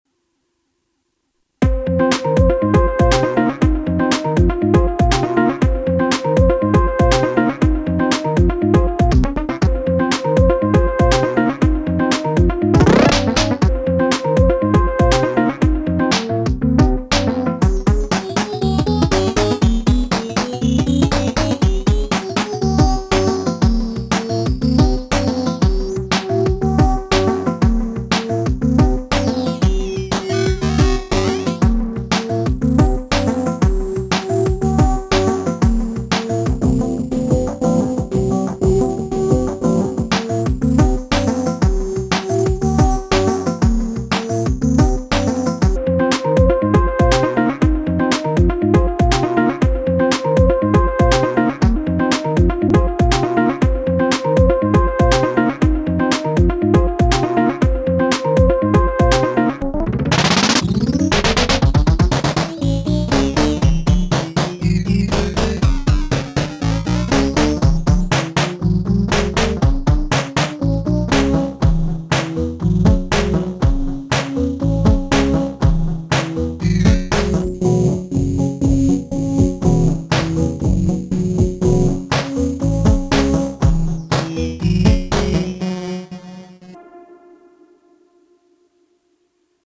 I noticed that the beat repeat effect makes a really nice lofi pitch shifter too. This is just one pattern smudged up a bit with some pitch shifting and sample rate reduction.